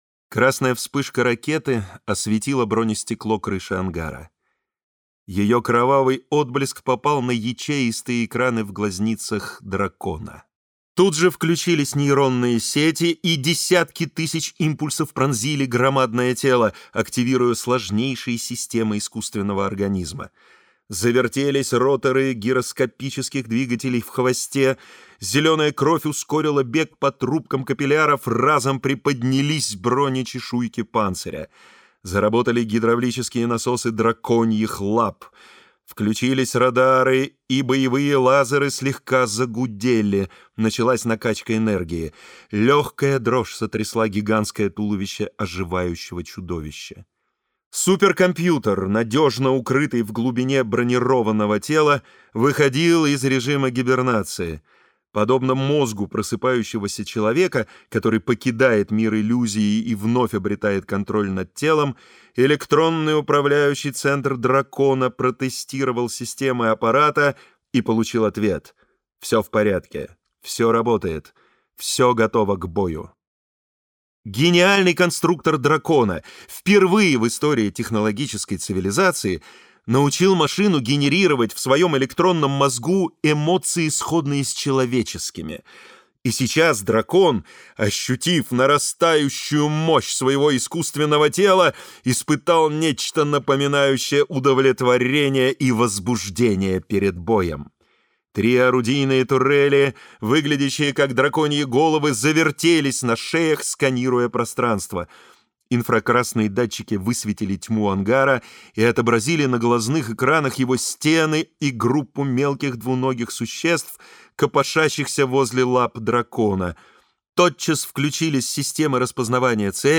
Аудиокнига Про драконов и людей | Библиотека аудиокниг
Прослушать и бесплатно скачать фрагмент аудиокниги